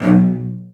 CELLOS.C#2-L.wav